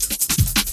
Pickup 03.wav